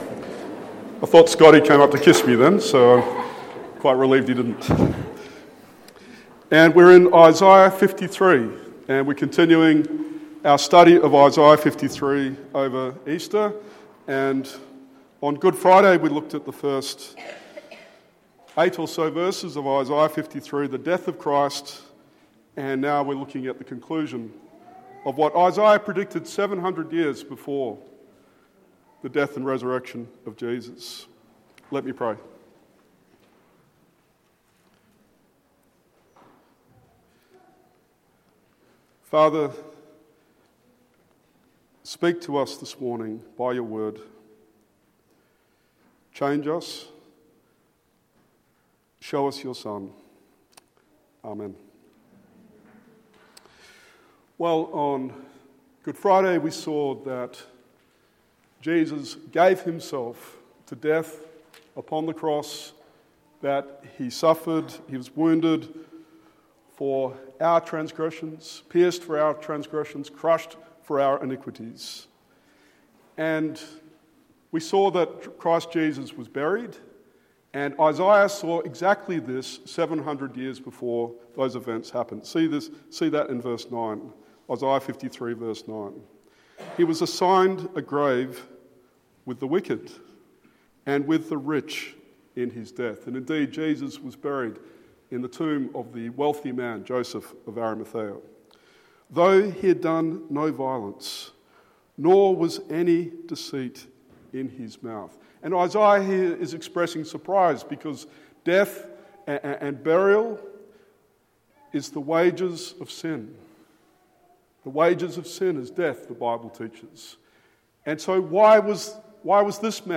Isaiah 52:13-53:12 Sermon